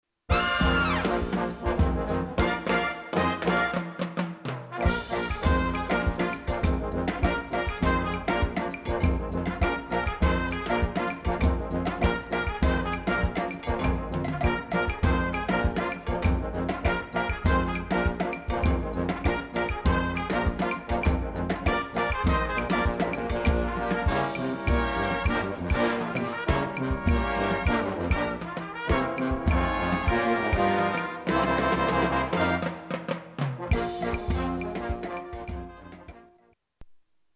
Innehåll: 81,8 % brass, 18,2 % slagverk.